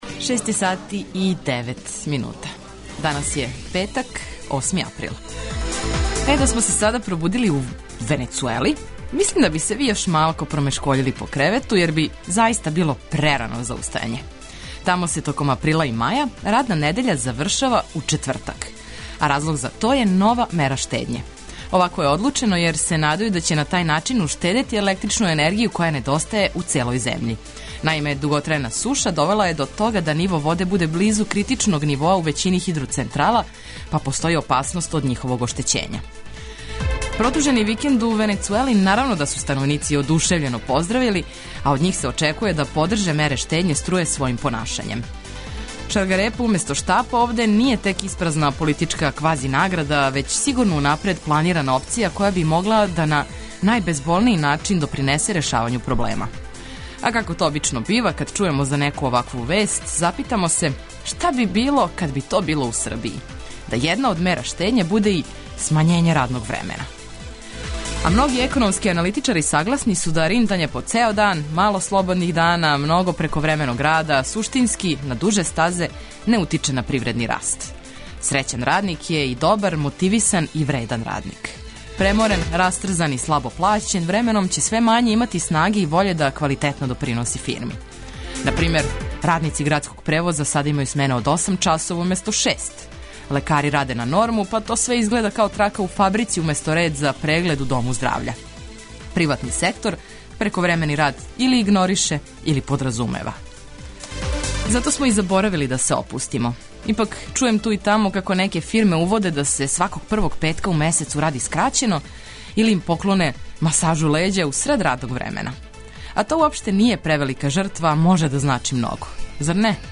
За почетак дана, ми ћемо се побринути да се разбудите у ведрини омиљене музике, и поткивањем свим битним информацијама, али ни смех никако нећемо заборавити!